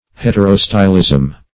Search Result for " heterostylism" : The Collaborative International Dictionary of English v.0.48: Heterostylism \Het`er*o*sty"lism\, n. (Bot.) The condition of being heterostyled.